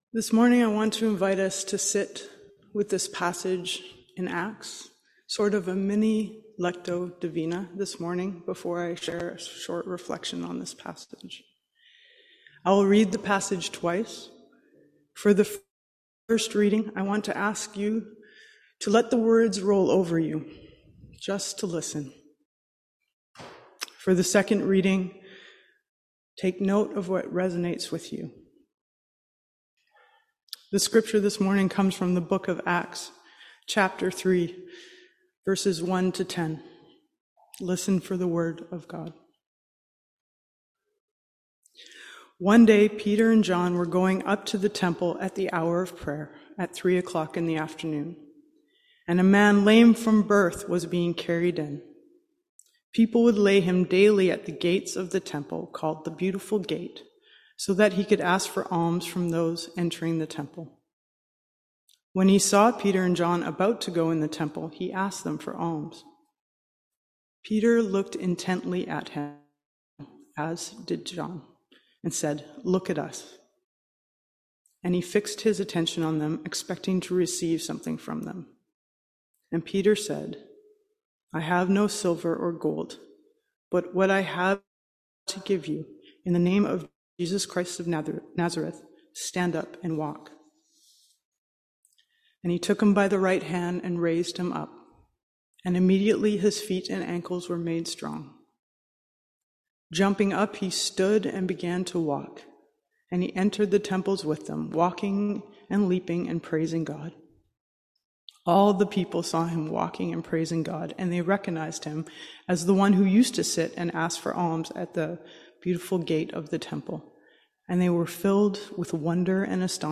Scripture and Sermon